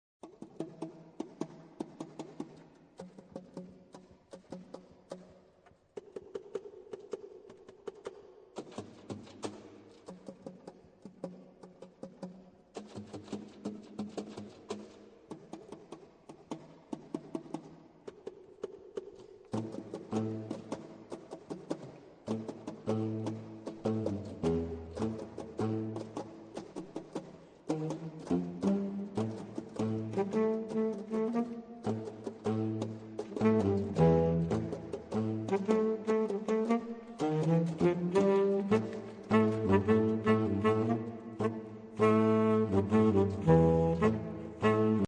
Six compositions for Saxophone Quartet
Obsazení: 4 Saxophone (AATBar)
Diese moderne Exkursion mit improvisierten Solos für alle